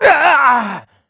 Riheet-Vox_Damage_kr_03.wav